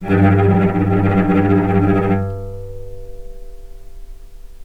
vc_trm-G2-pp.aif